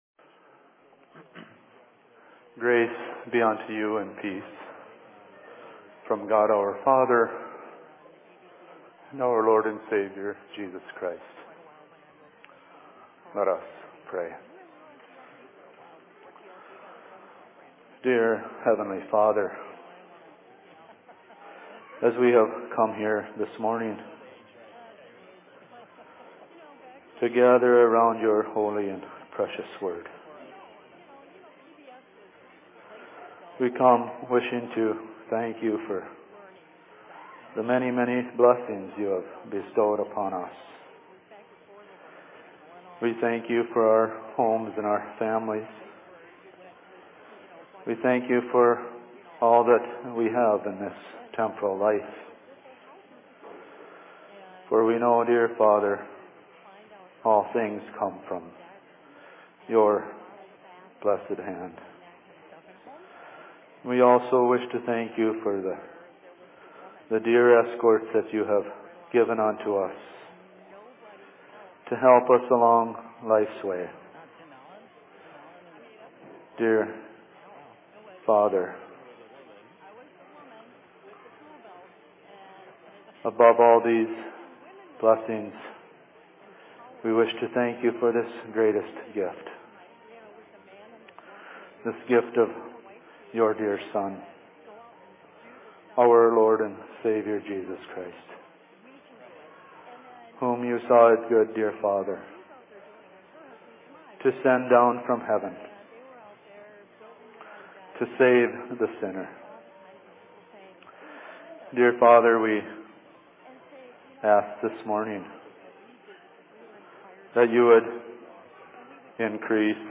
Sermon in Minneapolis 16.11.2014